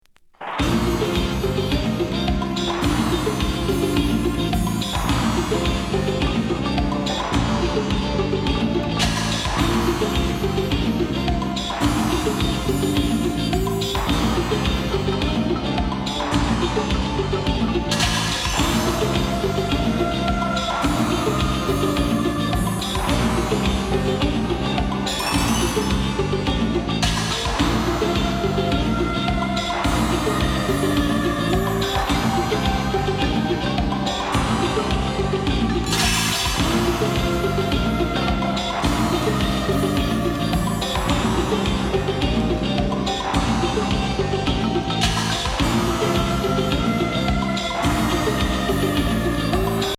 エスノ・レフティ・グルーヴ